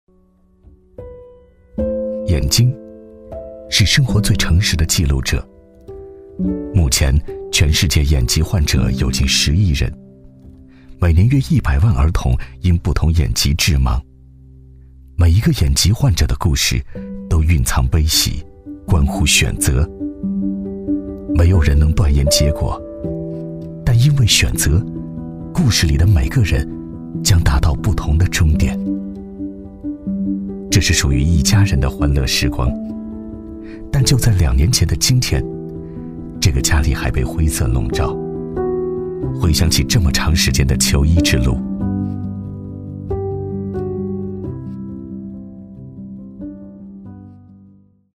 男42-微电影【眼疾患者的求医纪实（娓娓道来）】
男42-磁性质感 质感磁性
男42-微电影【眼疾患者的求医纪实（娓娓道来）】.mp3